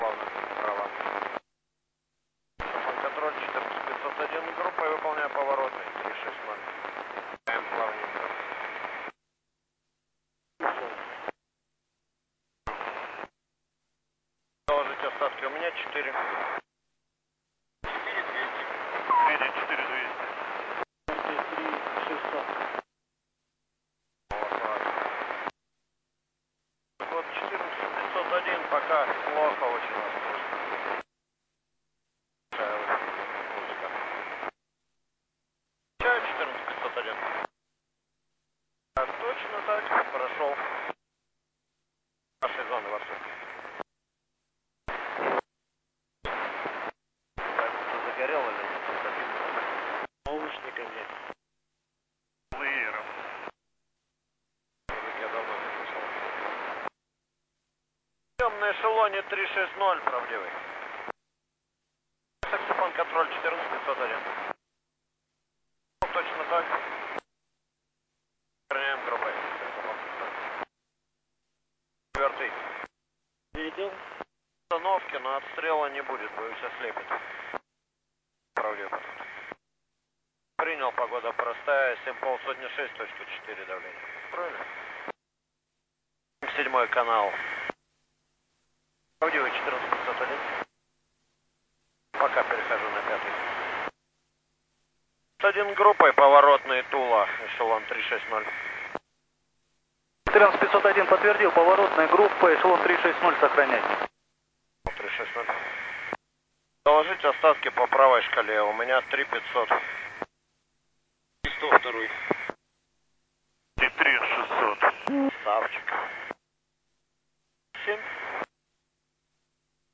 Начало » Записи » Записи радиопереговоров - авиация
тишина по 3-4 секунды очень затрудняет прослушивание... delete silence облегчает восприятие